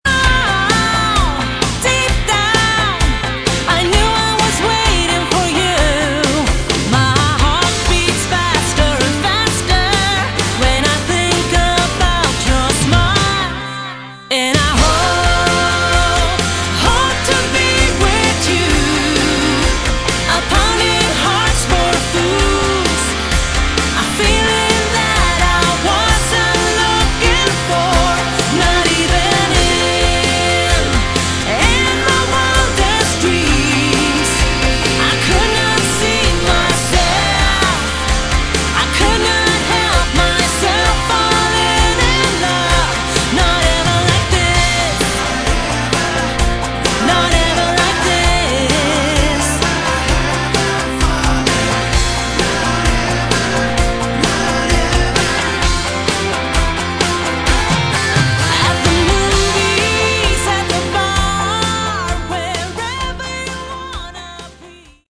Category: Melodic Rock
lead vocals
guitars, bass
acoustic guitars
keyboards, backing vocals, drums